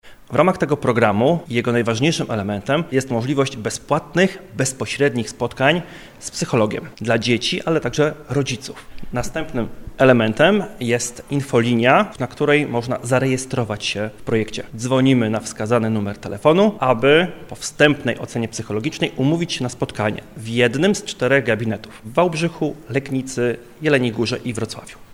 03_lekarz.mp3